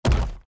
UI_Shock.mp3